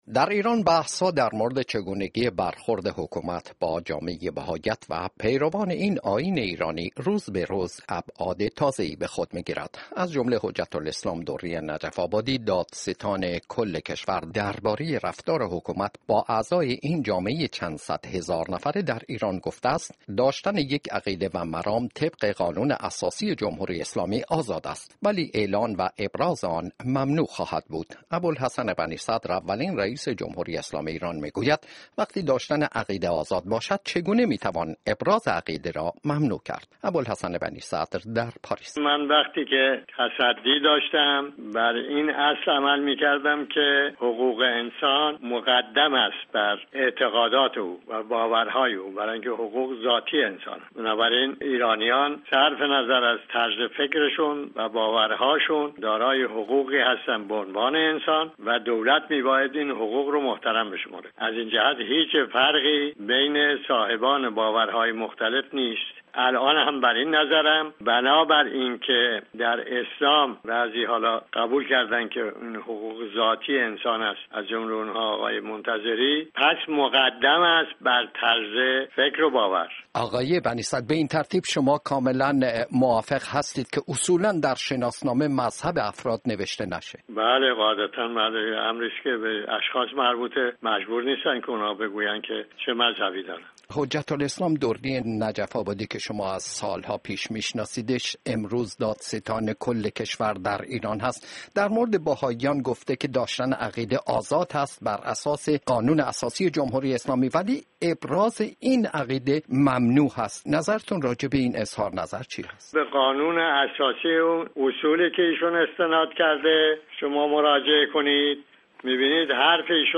Former President of Iran Mr. Bani-Sader on the rights of the Bahá’ís of Iran A four-minute interview with the former President of Iran.